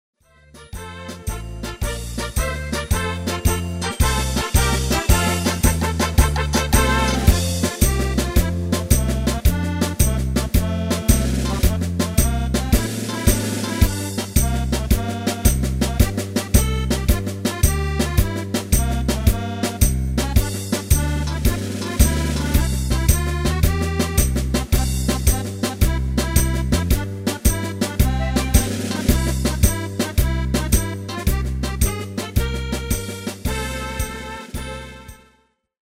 Demo/Koop midifile
Genre: Carnaval / Party / Apres Ski
- Géén vocal harmony tracks
Demo's zijn eigen opnames van onze digitale arrangementen.